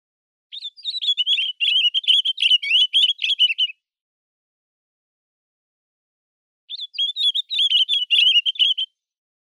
「日本の鳥百科」ギンザンマシコの紹介です（鳴き声あり）。